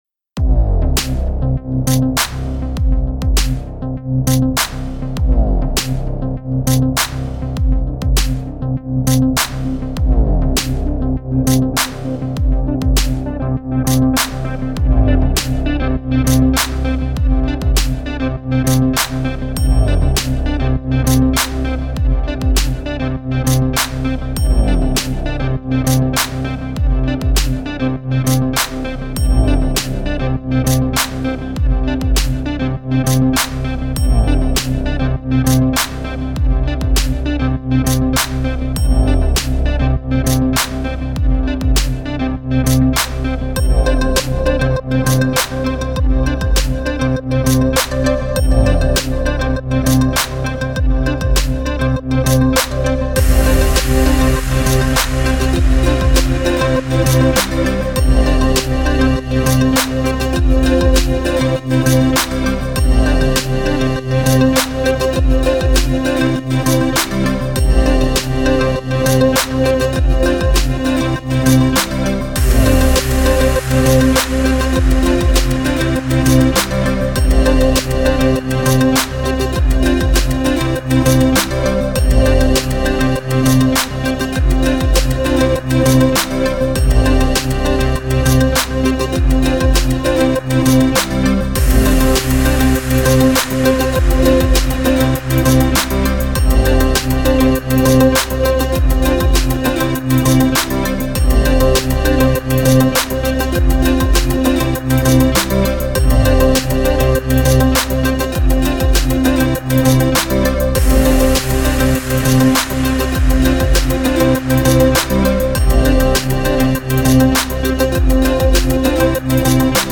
[Instrumental]